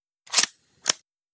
Sniper Bolt.wav